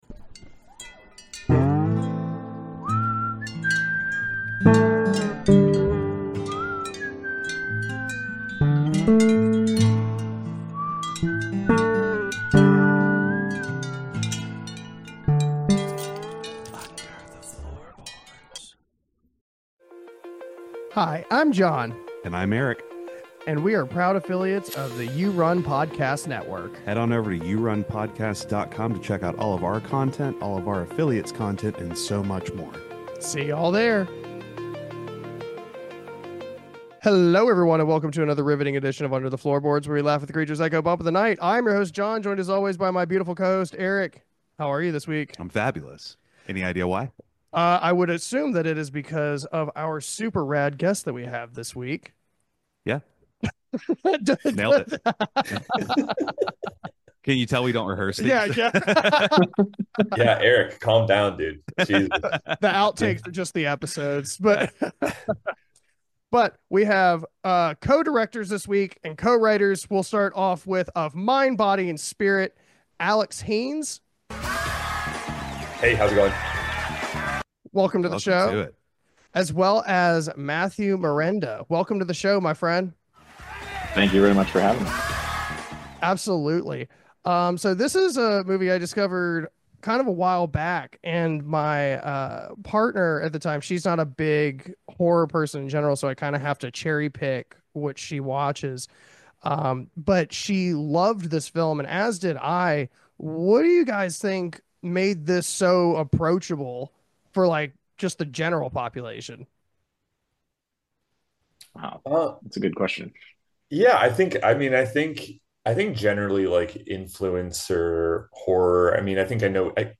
Mind Body Spirit (2023) - Interview